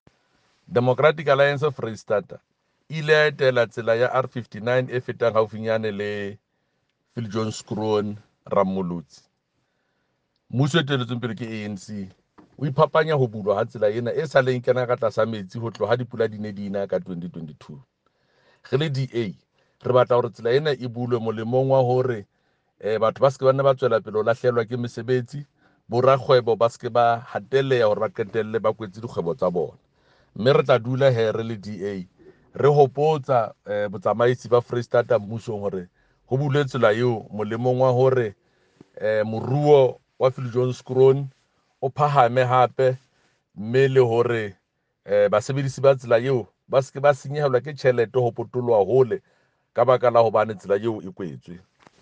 Sesotho soundbites by Jafta Mokoena MPL and
Sotho-voice-Jafta.mp3